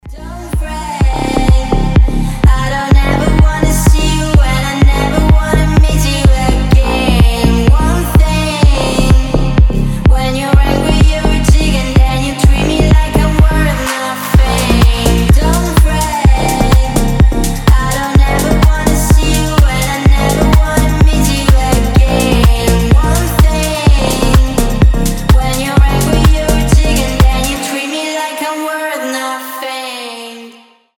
Клубные рингтоны
клубняк